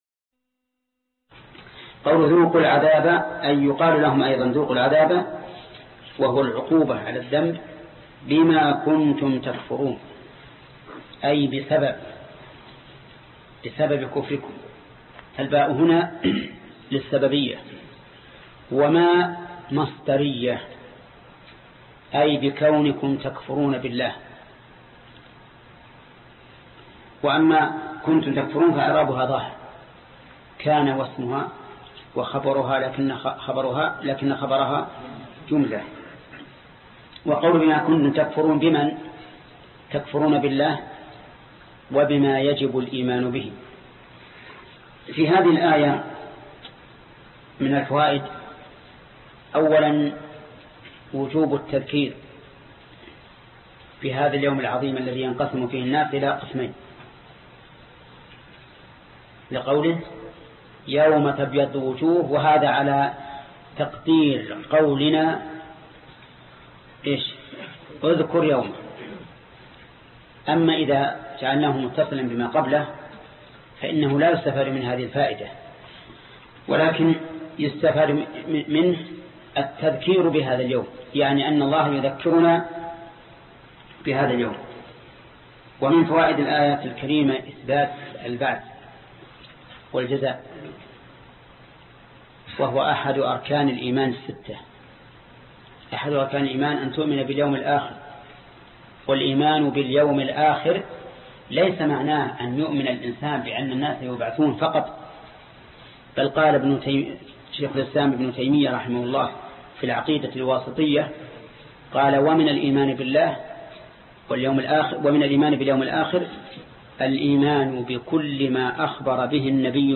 الدرس 181 فوائد الآية 106 (تفسير سورة آل عمران) - فضيلة الشيخ محمد بن صالح العثيمين رحمه الله